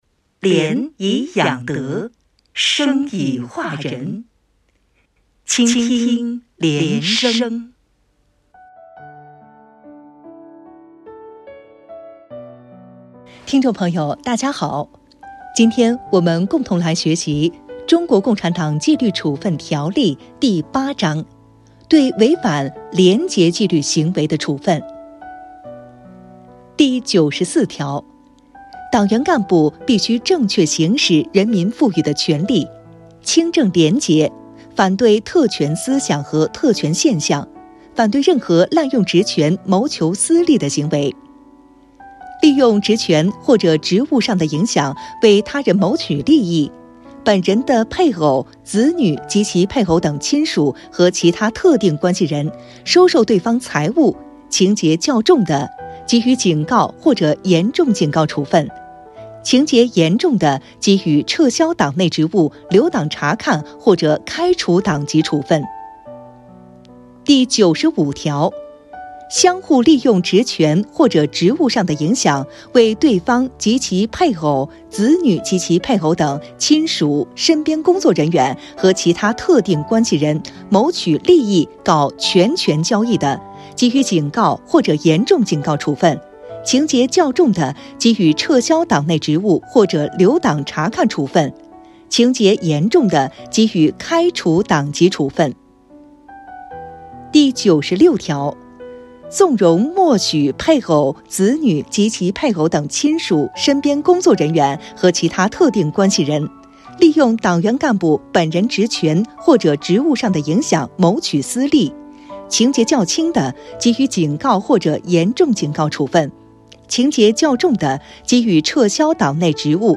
原文诵读系列音频